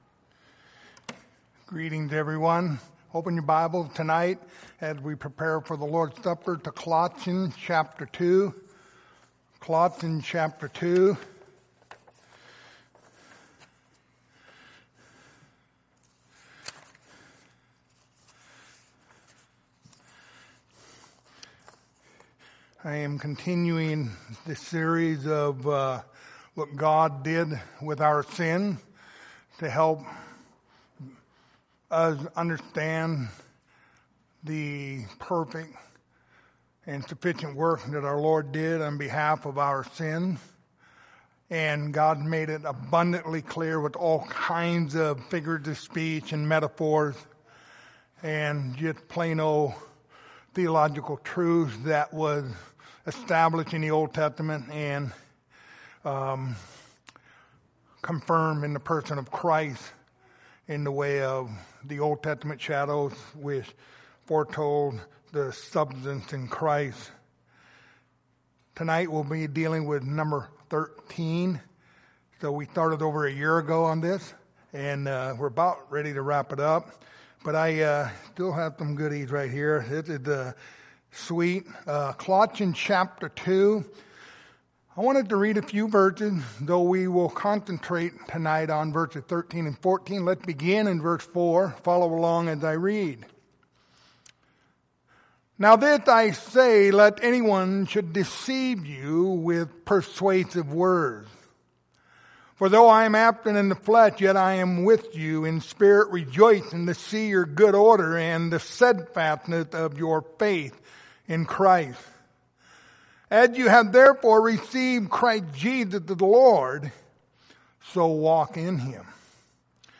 Lord's Supper Passage: Colossians 2:13-14 Service Type: Lord's Supper Topics